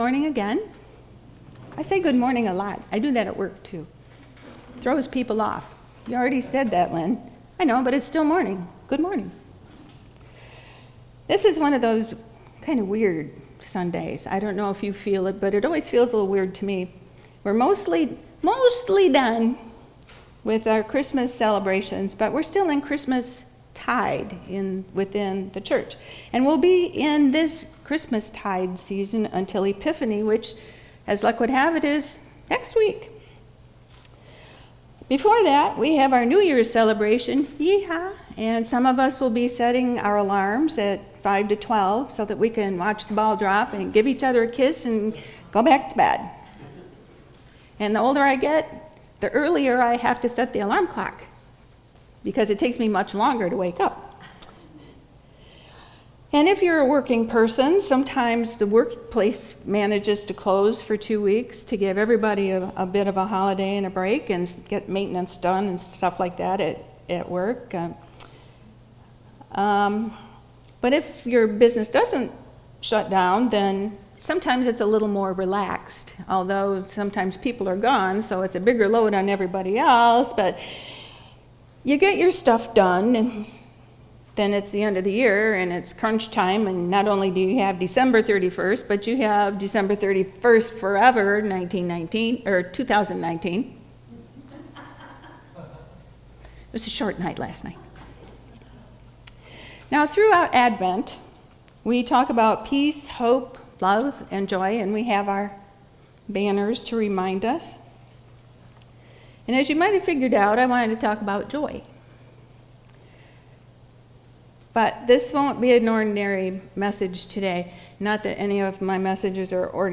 Almena United Methodist Church Sermon Archives